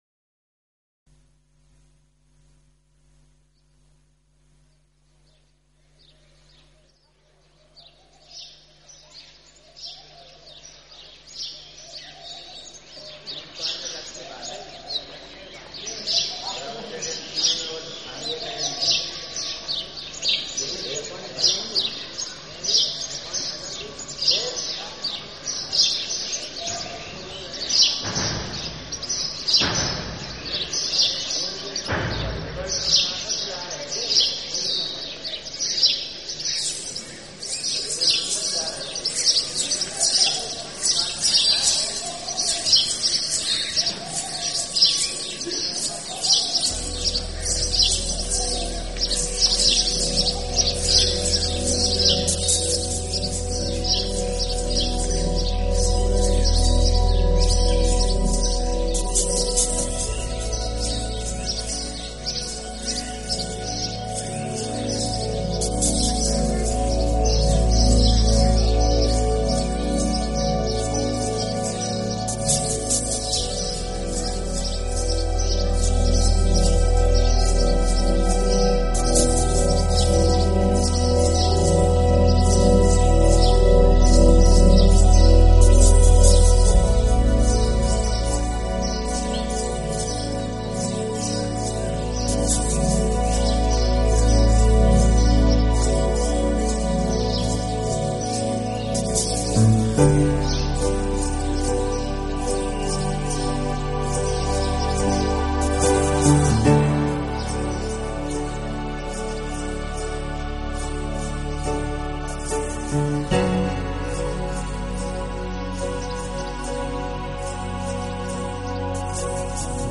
这种音乐是私密的，轻柔的，充满庄严感并总